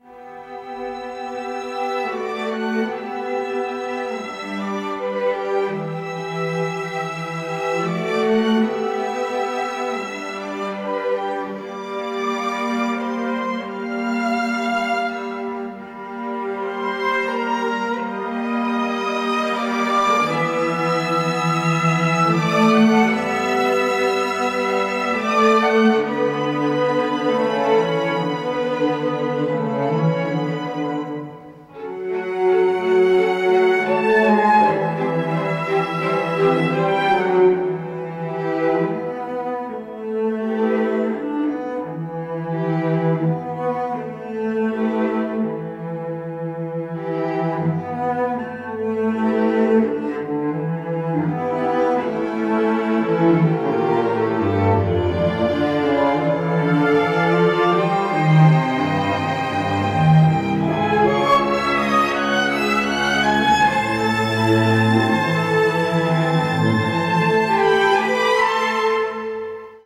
Strings